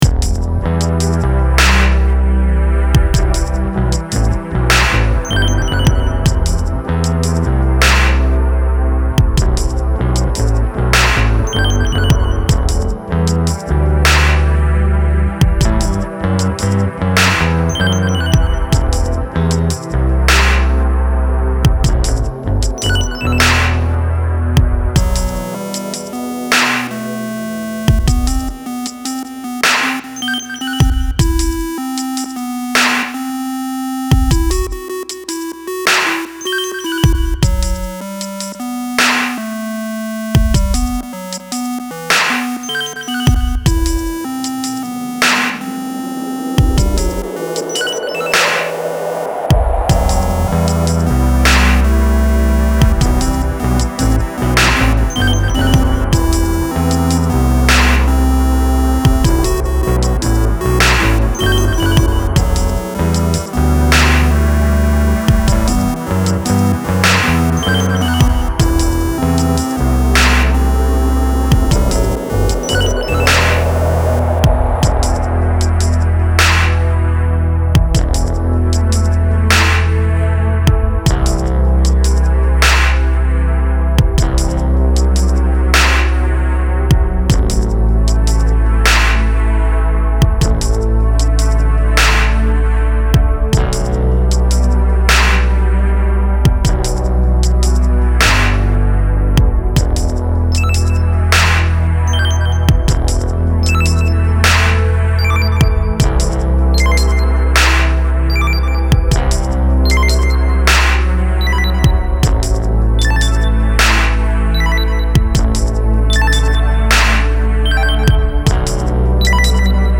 Style Style AmbientEDM/Electronic
Mood Mood Relaxed
Featured Featured BassDrumsSynth
BPM BPM 77